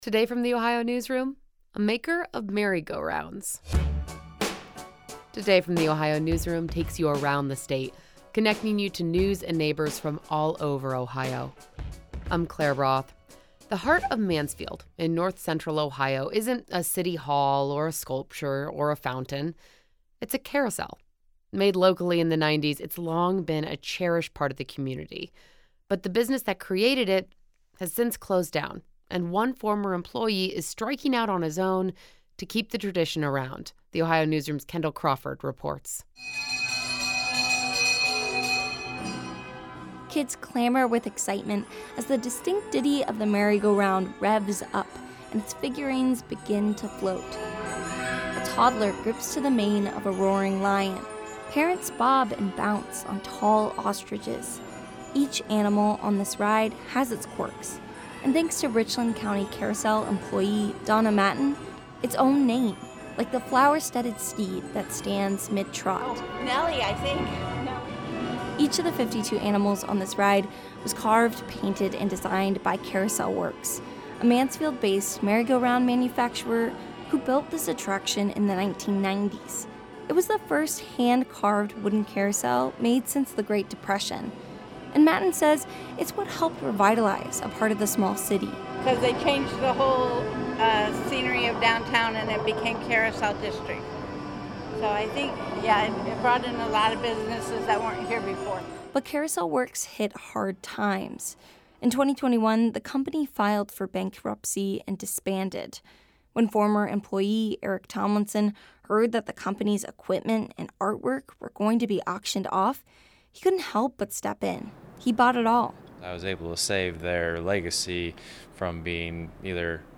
Kids clamor with excitement as the distinct ditty of the merry-go-round revs up and its figurines begin to float.
carousel-web.mp3